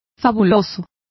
Complete with pronunciation of the translation of fabulous.